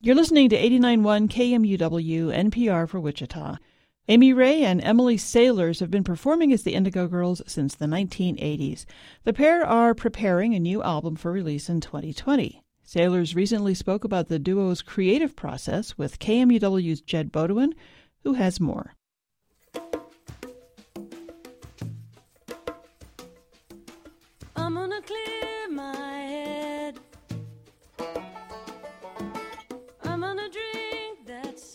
(captured from a webcast)